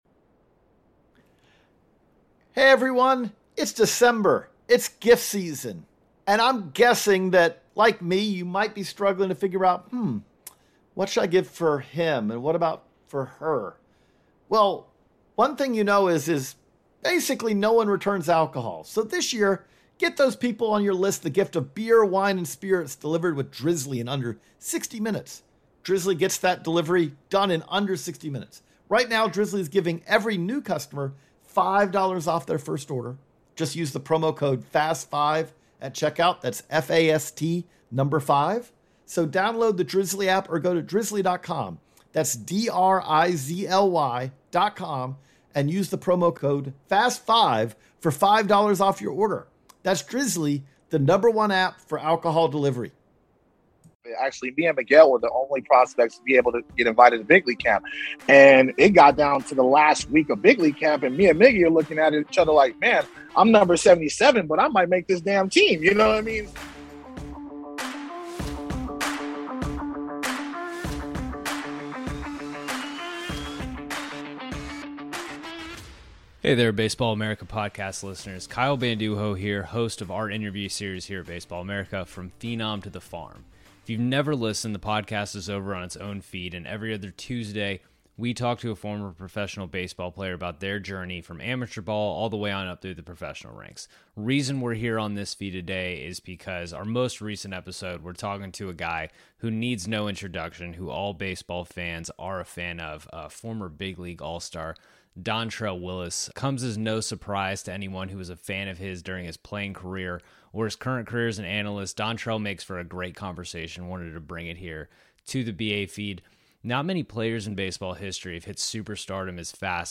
spoke with Dontrelle Willis about his rise from prospect to MLB world champion, Rookie of the Year & more.